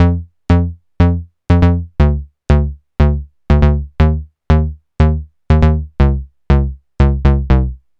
TSNRG2 Bassline 032.wav